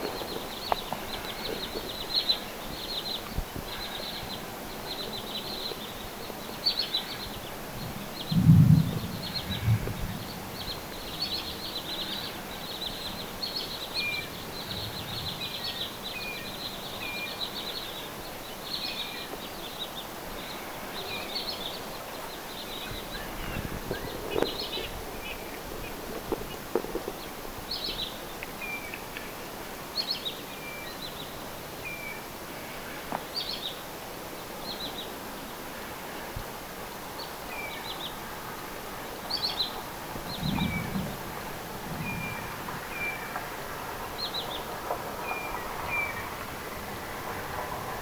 lirojen innokasta ääntelyä,
valkoviklo haikeasti ääntelee
lirojen_innokasta_aantelya_valkoviklo_haikeasti_aantelee.mp3